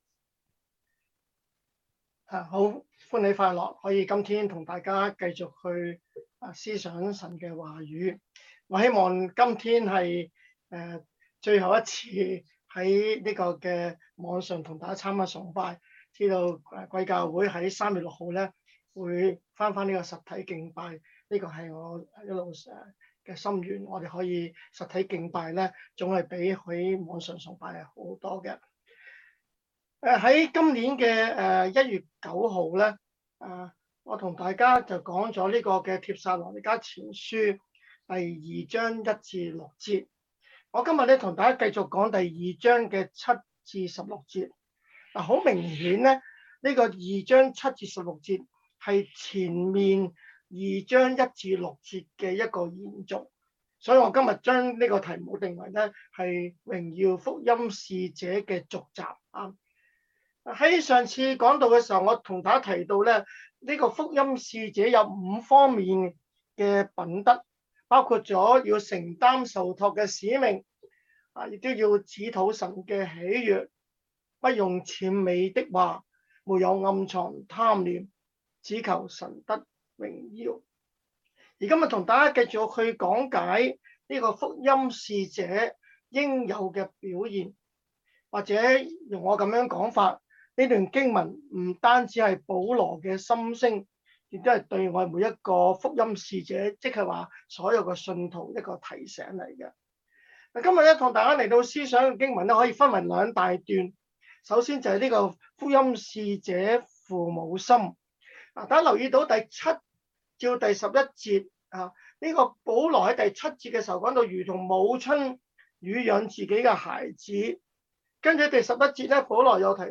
sermon0213canto.mp3